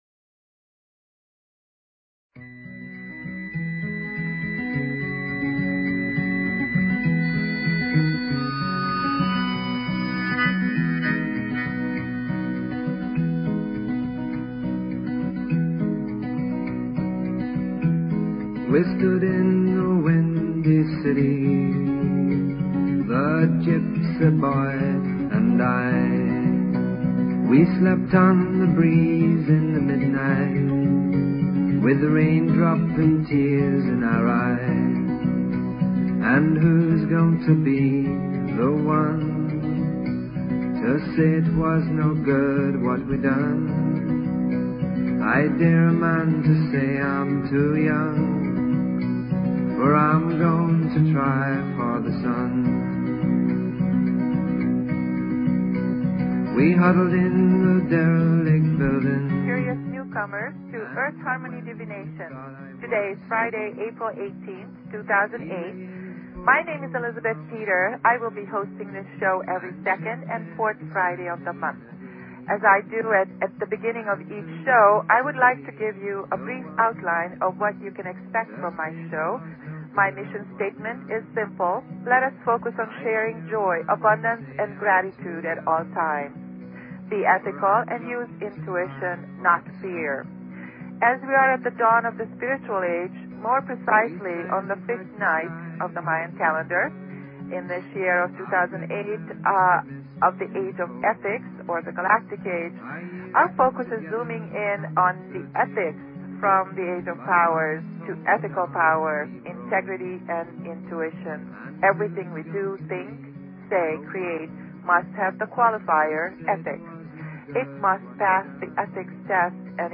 Talk Show Episode, Audio Podcast, Earth_Harmony_Divinations and Courtesy of BBS Radio on , show guests , about , categorized as
Show Headline Earth_Harmony_Divinations Show Sub Headline Courtesy of BBS Radio Imagine airplane speeds, luxury seating, and traveling with your car, not in your car..: on Mass Tram, a tested suspension based tram transport system that is energy positive. Interview